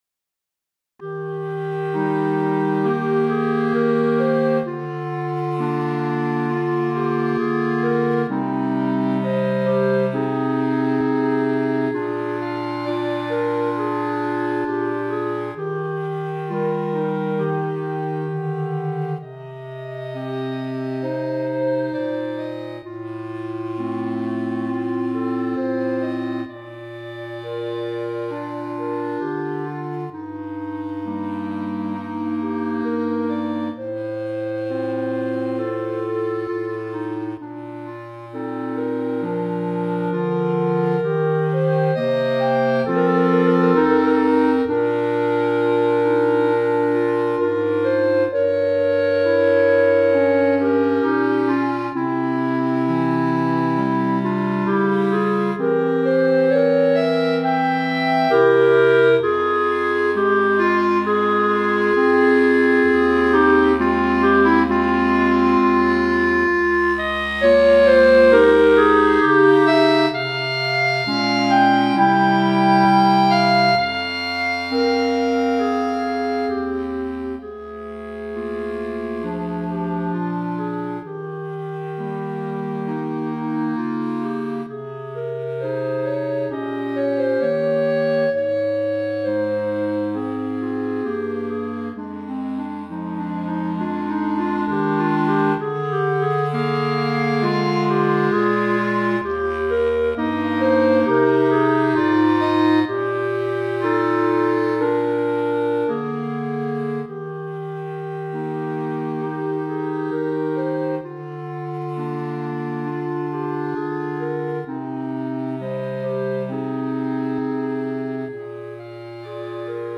Voicing: Clarinet Quartet